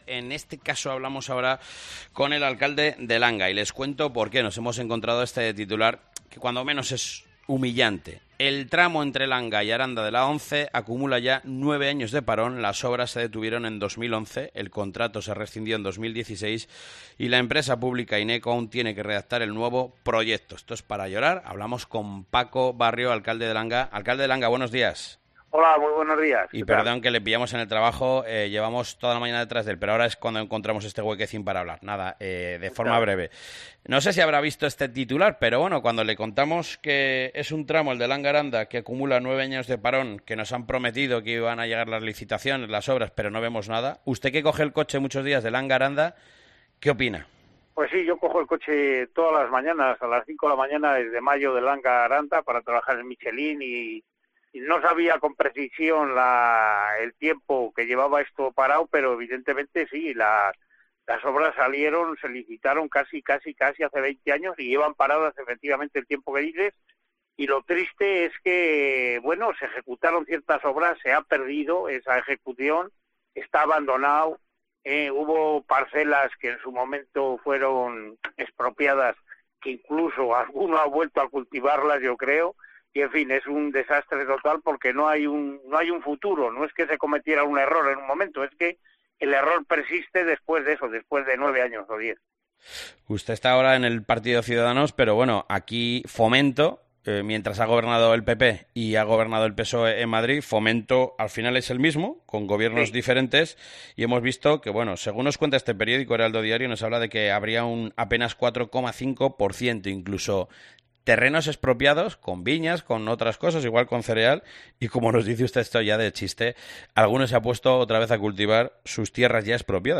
ENTREVISTA Alcalde de Langa Autovía A-11